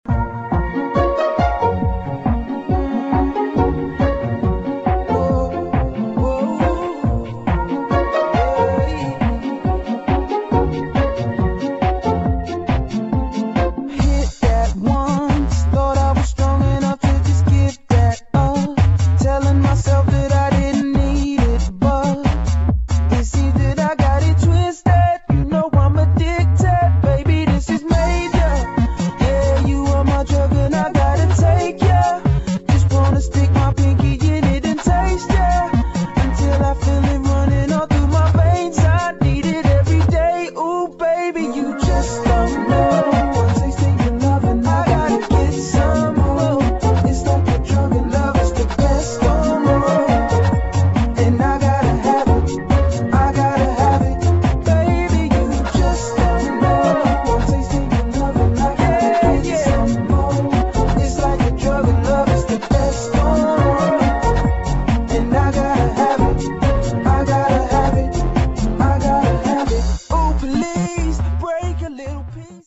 [ UK GARAGE / UK FUNKY ]